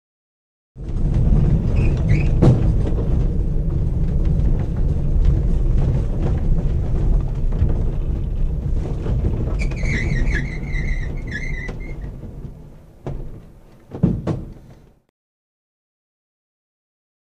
Glider; Interior; Interior Glider Landing. Light Rumble With Occasional Squeak.